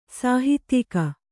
♪ sāhitya